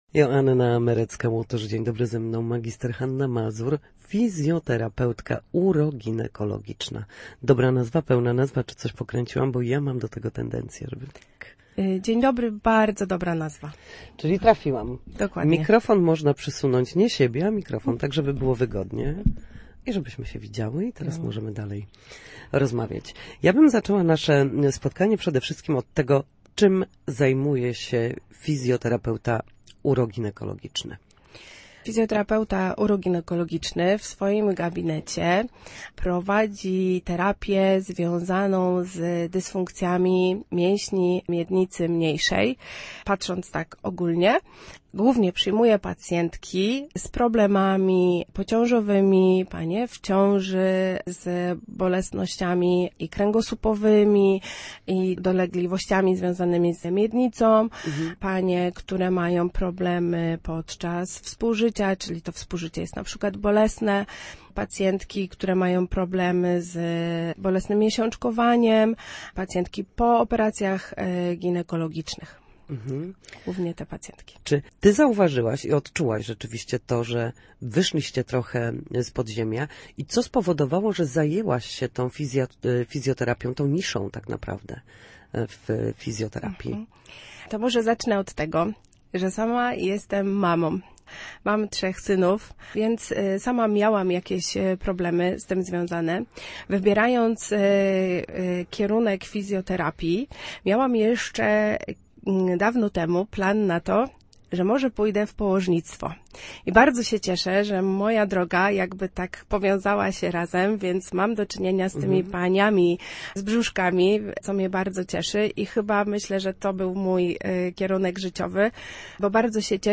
W każdą środę w popołudniowym Studiu Słupsk Radia Gdańsk dyskutujemy o tym, jak wrócić do formy po chorobach i urazach.
W audycji „Na zdrowie” nasi goście — lekarze i fizjoterapeuci — odpowiadają na pytania dotyczące najczęstszych dolegliwości, podpowiadają, jak leczyć się w warunkach domowych, i zachęcają do udziału w nowych, ciekawych projektach.